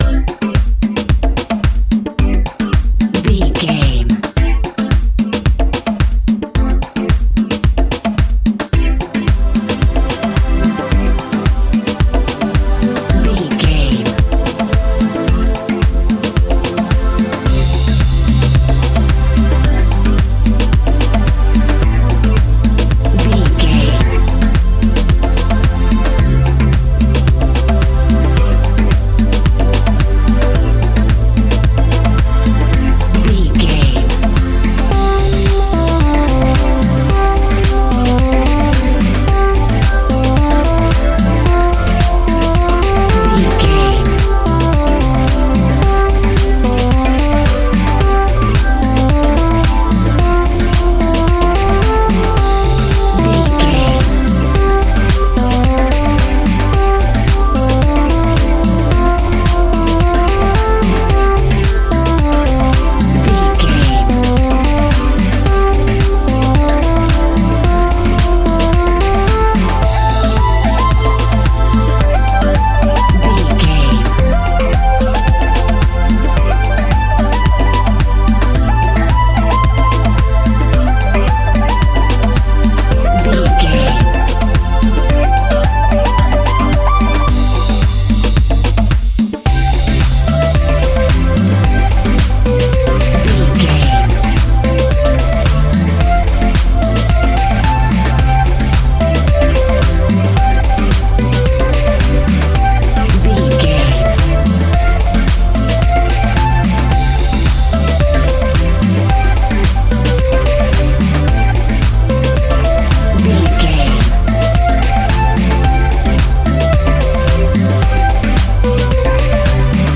Ionian/Major
uplifting
futuristic
hypnotic
dreamy
tranquil
smooth
drum machine
electronica
synth leads
synth bass
synth pad
robotic